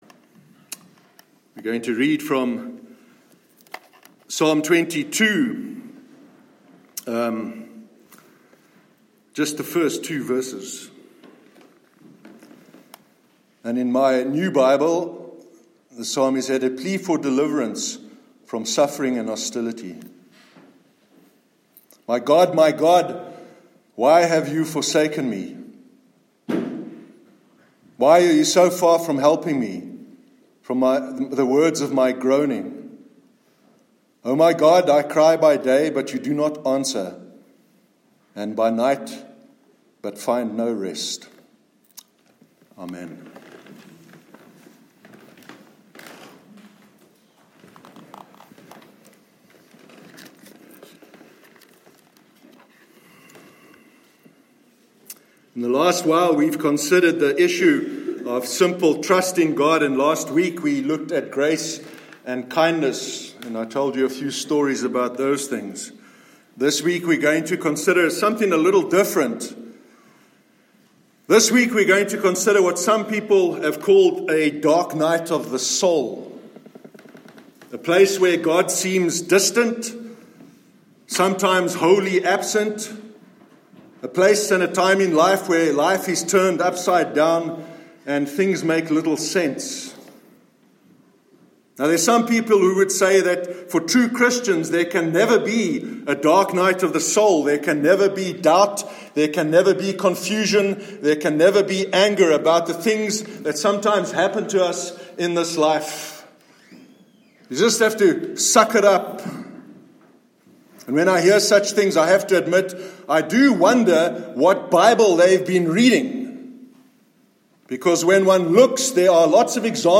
A Dark Night of the Soul- Sermon 30th June 2019